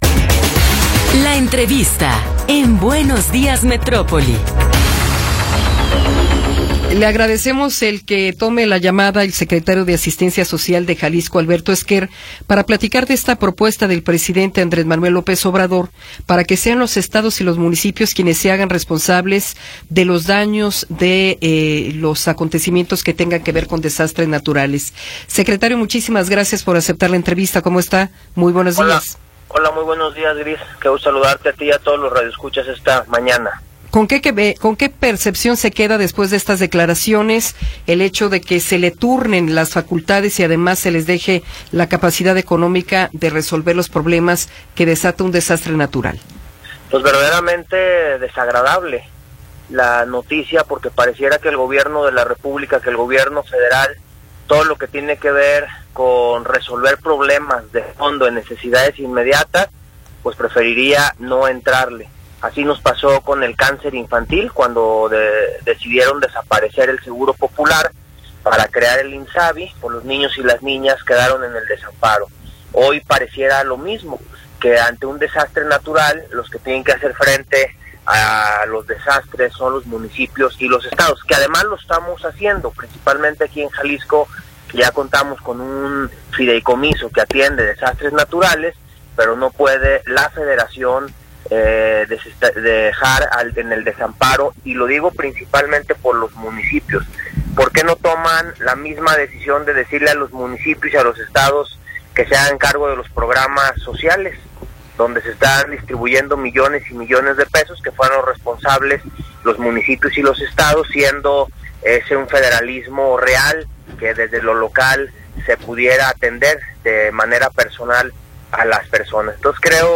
Entrevista con Alberto Esquer Gutiérrez